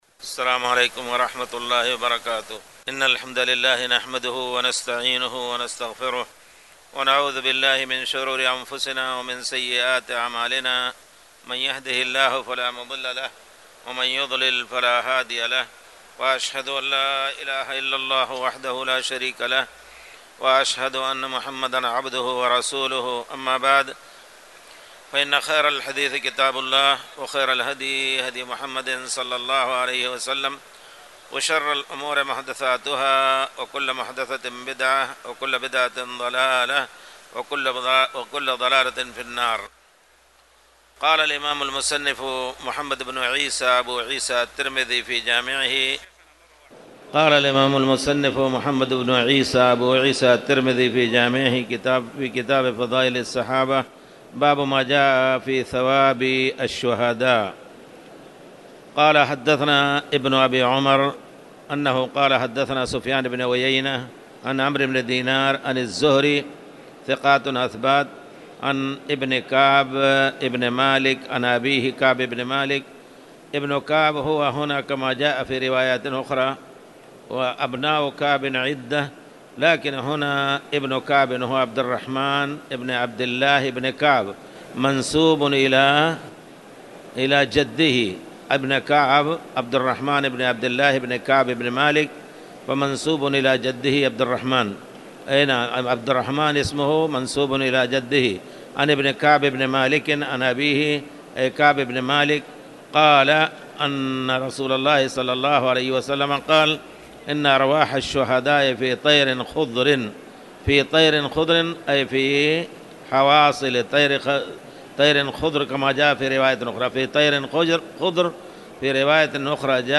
تاريخ النشر ١ شعبان ١٤٣٨ هـ المكان: المسجد الحرام الشيخ